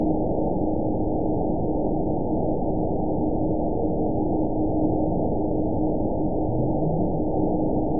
event 921956 date 12/23/24 time 08:29:46 GMT (4 months, 2 weeks ago) score 9.28 location TSS-AB02 detected by nrw target species NRW annotations +NRW Spectrogram: Frequency (kHz) vs. Time (s) audio not available .wav